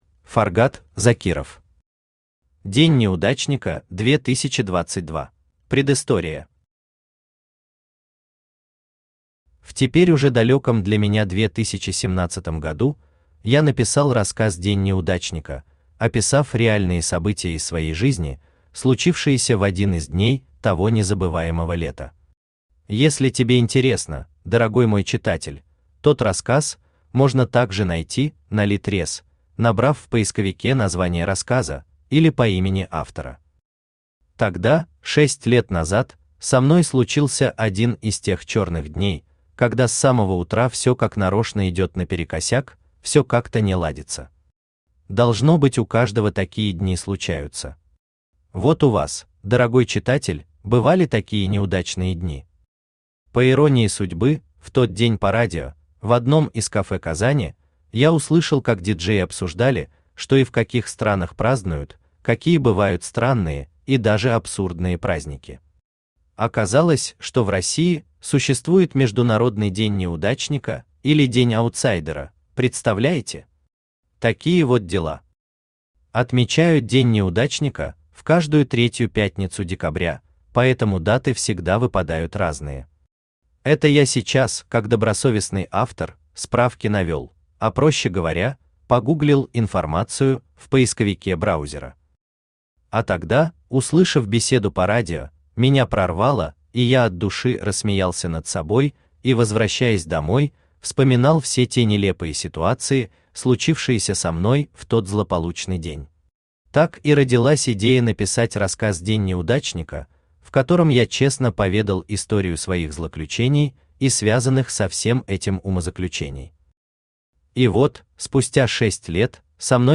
Аудиокнига День неудачника 2022 | Библиотека аудиокниг
Aудиокнига День неудачника 2022 Автор Фаргат Закиров Читает аудиокнигу Авточтец ЛитРес.